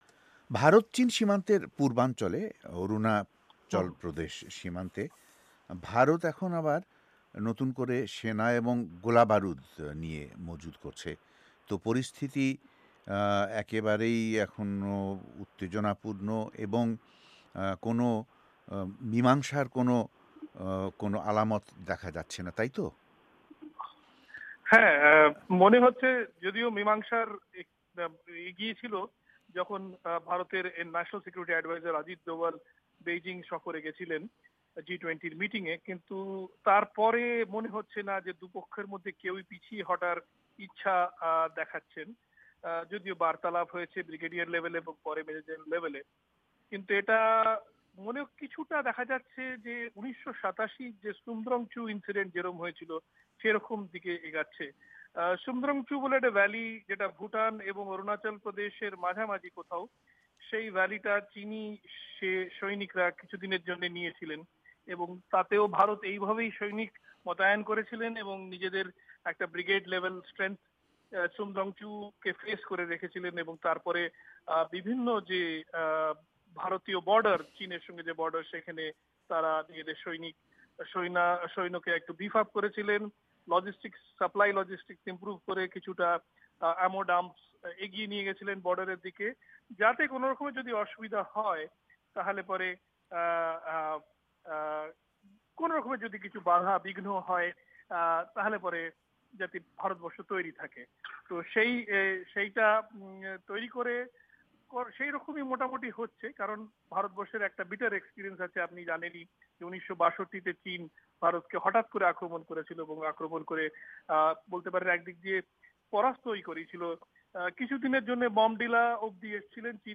ভয়েস অফ এ্যামেরিকার ওয়াশিংটন স্টুডিও থেকে আজ রবিবার তাঁর সঙ্গে টেলিফোনে কথা বলেন